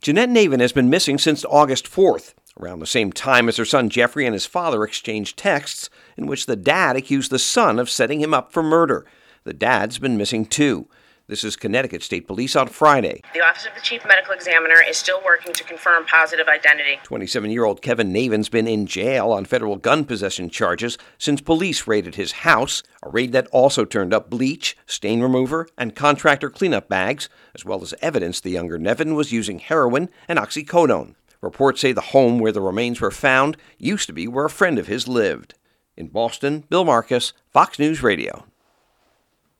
(BOSTON) OCT 30 – AUTHORITIES IN CONNECTICUT SAY THE REMAINS OF TWO BODIES FOUND IN A VACANT HOUSE MAY BE A COUPLE THAT DISAPPEARED THREE MONTHS AGO. A FEDERAL PROSECUTOR SAYING THAT THEIR SON IS A SUSPECT IN THEIR MURDERS. FOX NEWS RADIO’S